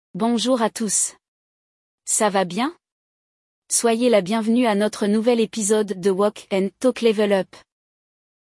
O episódio de hoje vai mostrar um diálogo entre duas pessoas, no qual uma encoraja a outra a ir até a delegacia para prestar uma queixa.